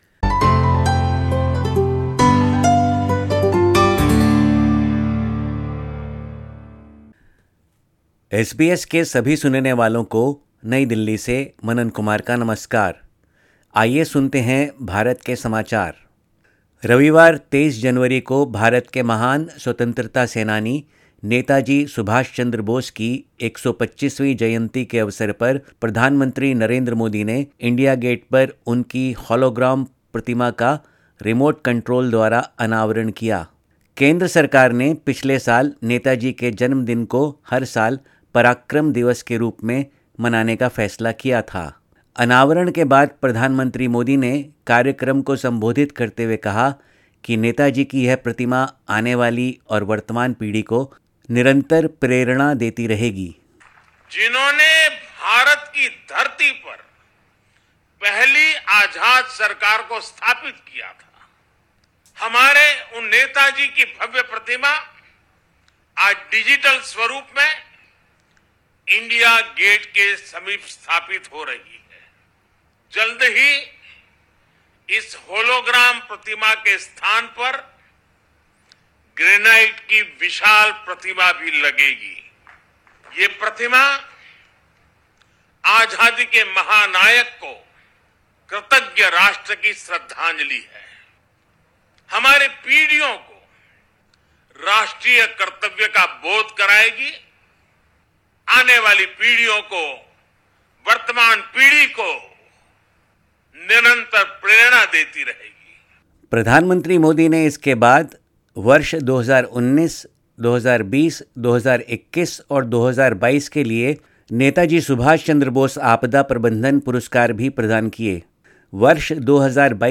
24_jan_22_sbs_india_hindi_news_bulletin.mp3